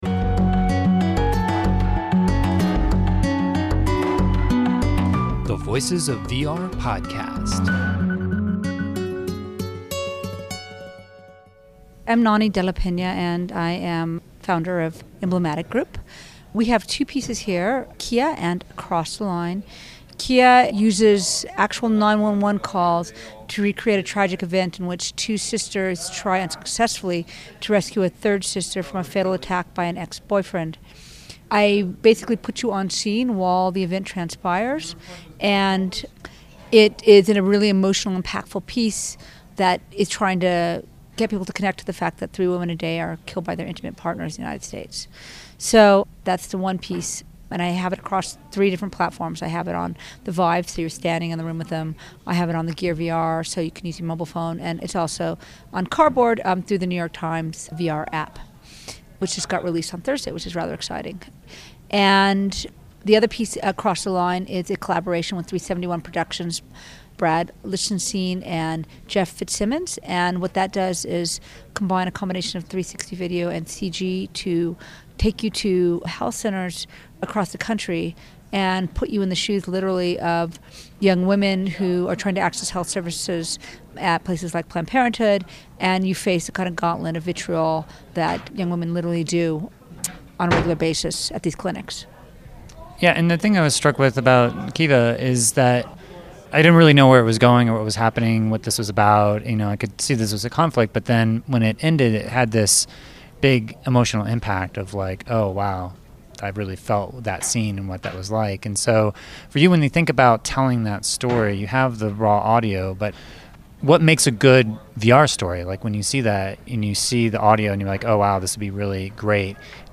I had a chance to catch up with Nonny at Sundance to get more insight into how she cultivates empathy within VR, but also why she’s so motivated to always stand up for the underdog within her work.